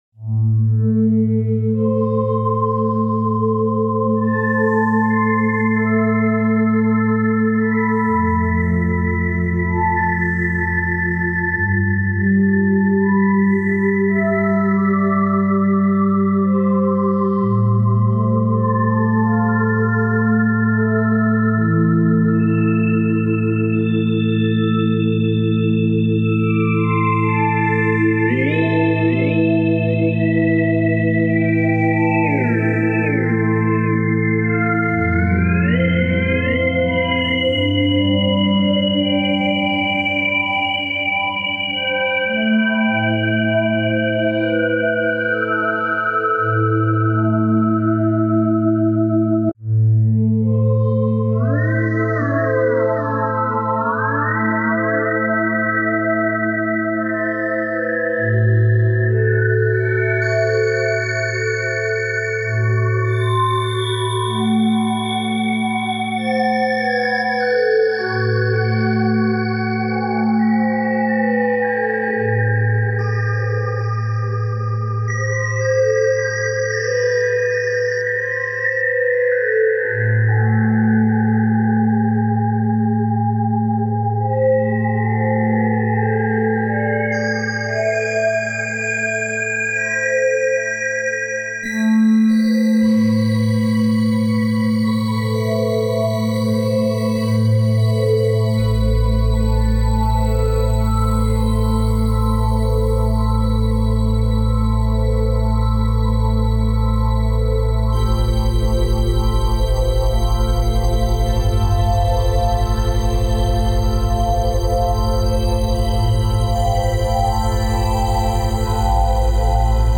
Вложения Yamaha Montage - FM-X JS-SOUND Presets JMJ Style.mp3 Yamaha Montage - FM-X JS-SOUND Presets JMJ Style.mp3 7,3 MB · Просмотры: 818